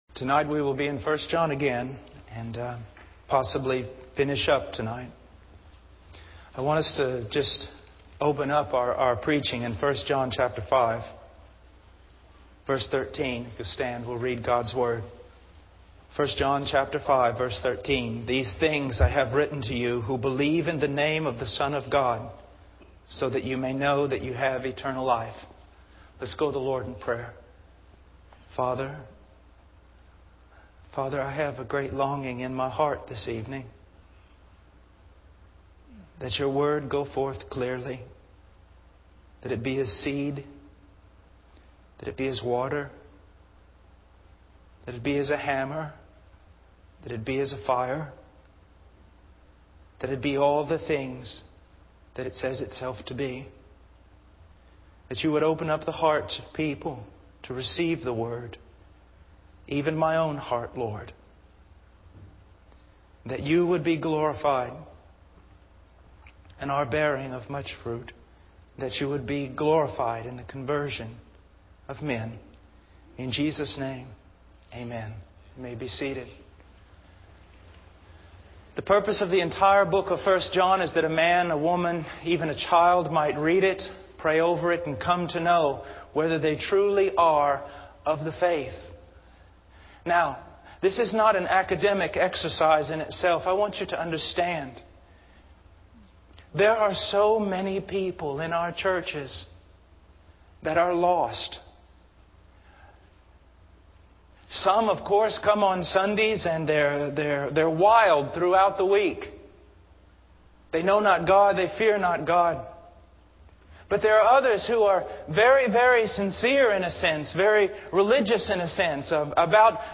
In this sermon, the preacher emphasizes the fleeting nature of life and the importance of living in accordance with God's will. He warns that death and hell are approaching and urges the audience to consider how they should live in light of this reality. The preacher highlights the temporary nature of worldly achievements and possessions, comparing them to the passing empires of Rome and Babylon.